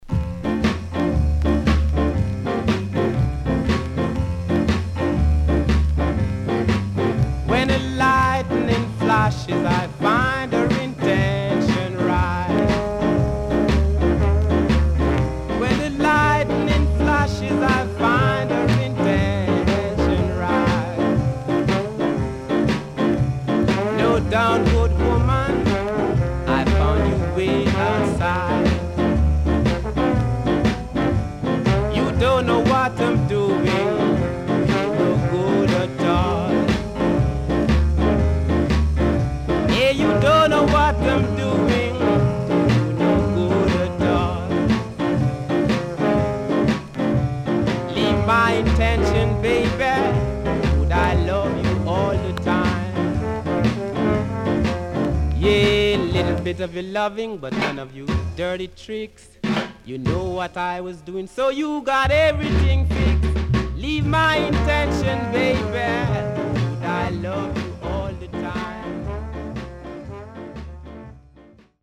Rare.Killer Jazzy Inst & Good Shuffle Vocal.W-Side Good
SIDE A:少しチリノイズ入ります。